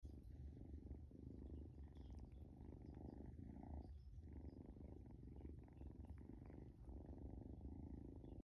Purr Machine: Kitten At 9 Sound Effects Free Download